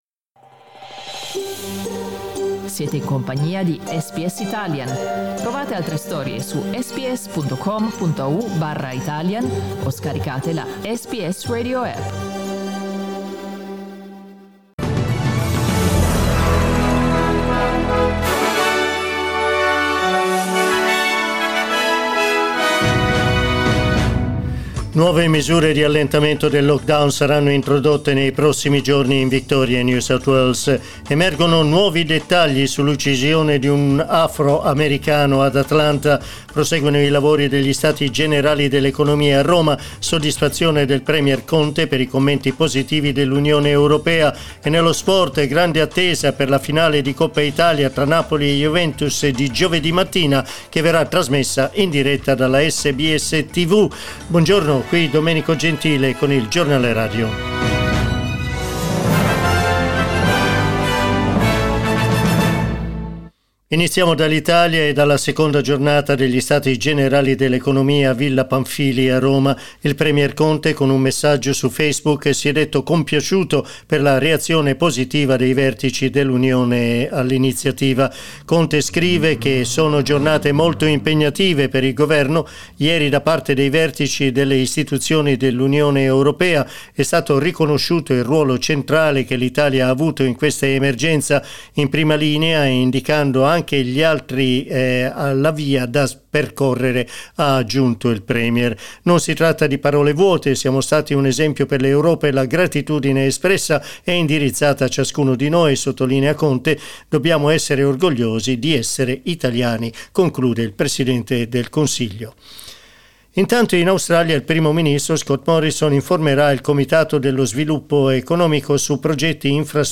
Giornale radio lunedì 15 giugno
news_podcast.mp3